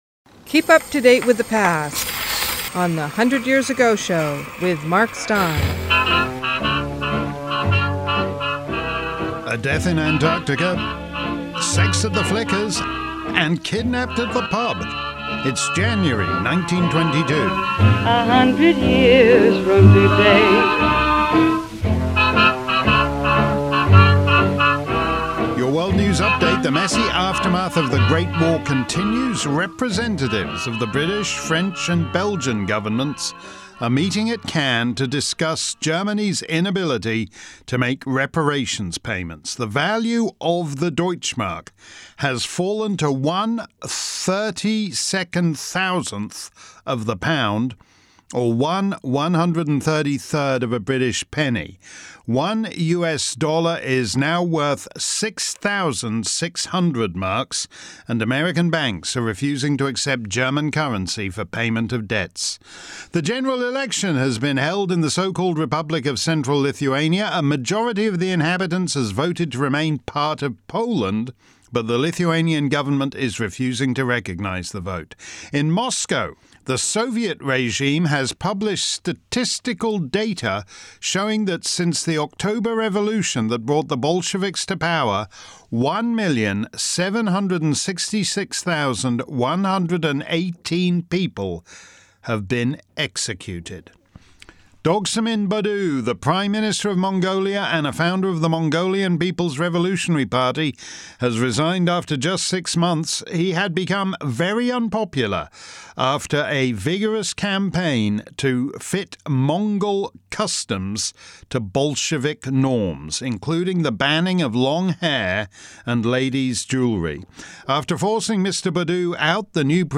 Oh, and plenty of period music - many songs that are still sung, but played in the versions that first made them hits.